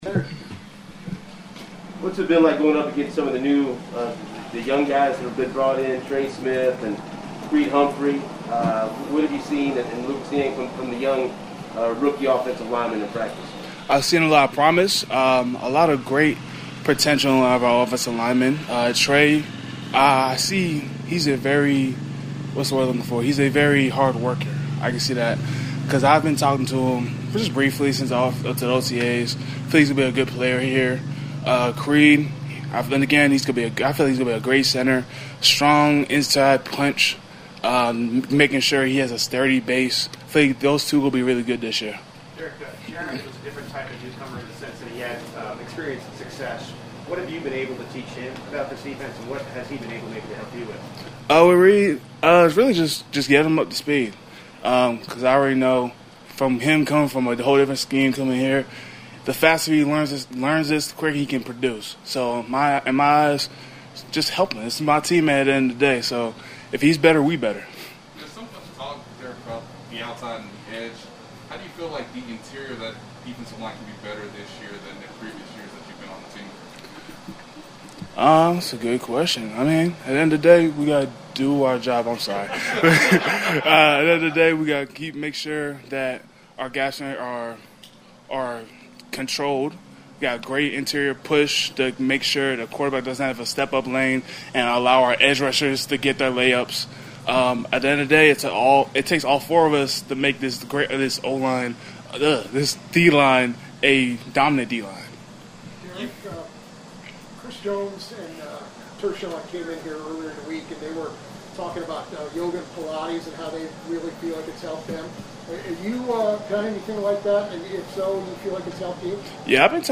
Derrick Nnadi visits with the media after Thursdays practice.